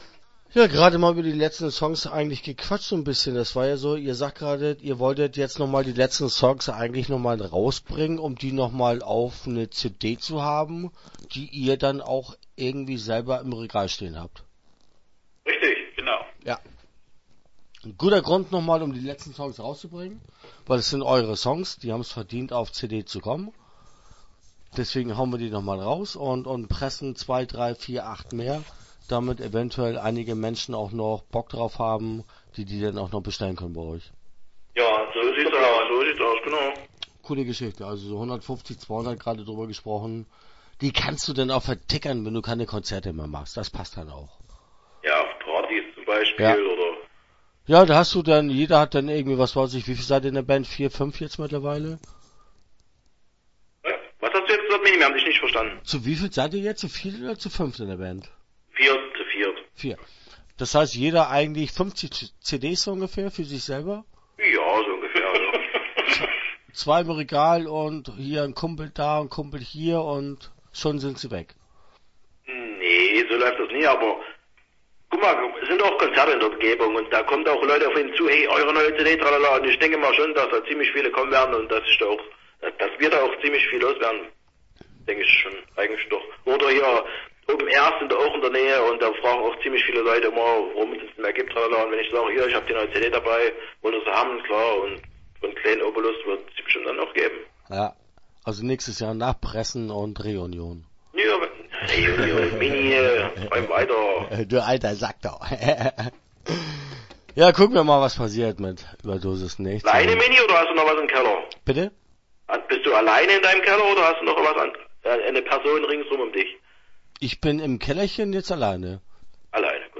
Start » Interviews » ÜberDosisNichts